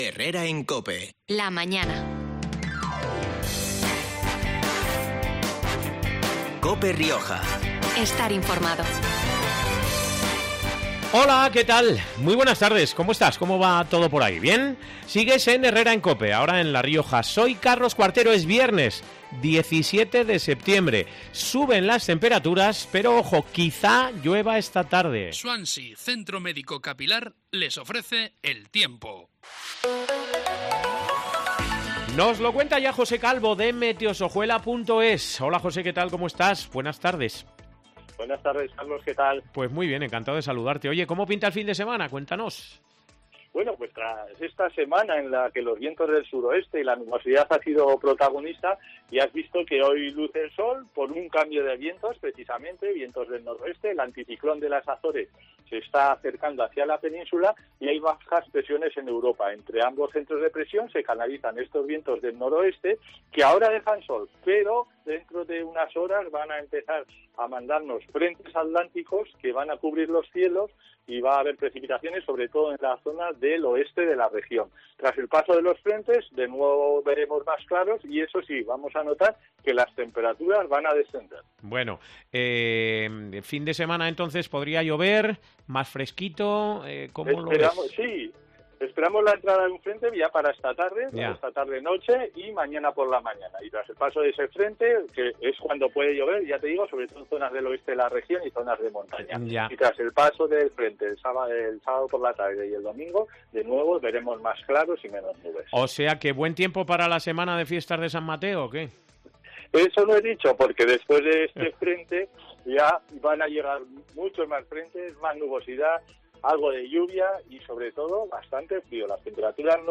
Carmen Quintanilla: AFAMMER trabaja para feminizar el campo En declaraciones a COPE Rioja minutos antes de firmar ese acuerdo, Quintanilla ha dicho que su organización trabaja, entre otras cosas, para “feminizar el campo” .